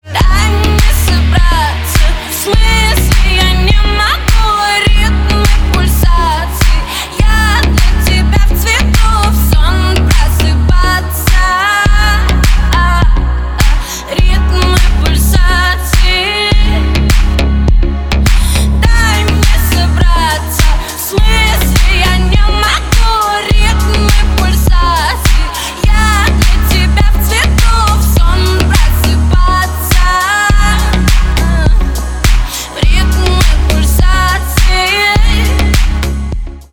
• Качество: 320, Stereo
поп
громкие
женский вокал
deep house
dance
club
чувственные